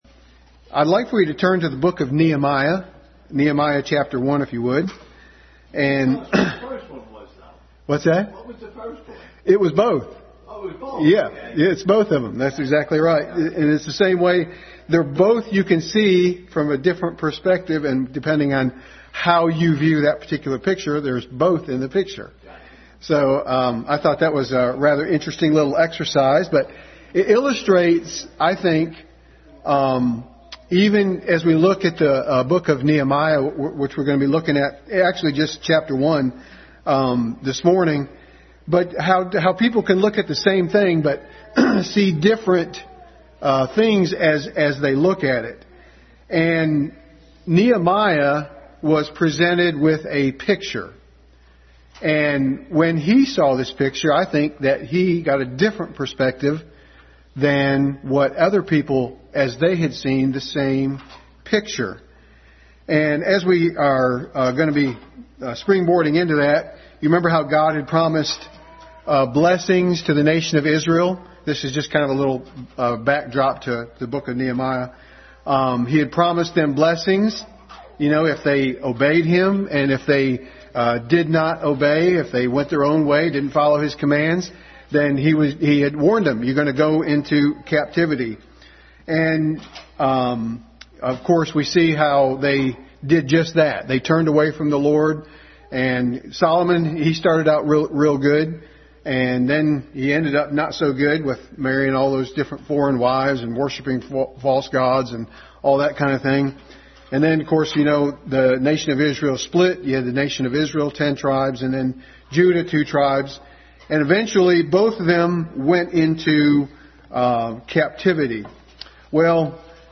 Nehemiah 1:1-11 Passage: Nehemiah 1:1-11, 6:15-16 Service Type: Sunday School